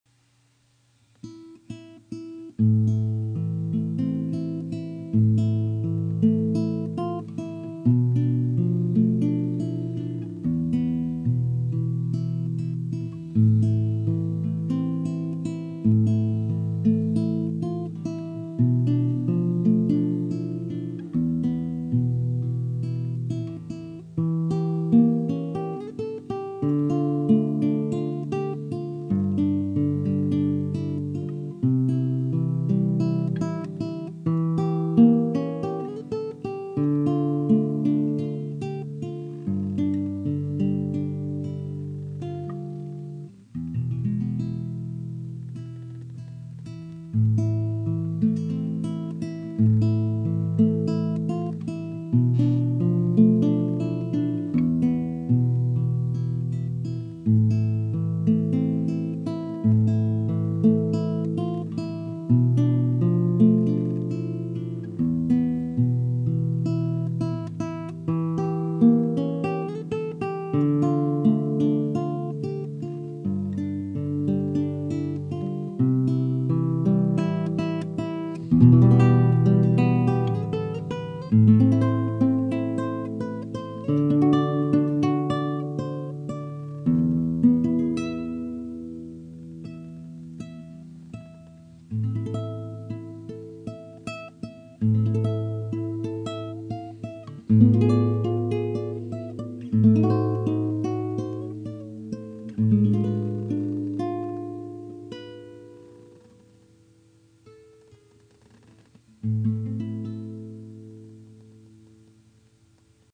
Guitare Classique
La première partie est facile et la deuxième plus difficile, mais le caractère de la pièce demande un jeu contrasté ...
Je viens de faire le deuxième avec la même guitare et les mêmes ongles coupés à la scie ...
c'est vraiment joli :biggrin: un peu triste mais joli :biggrin: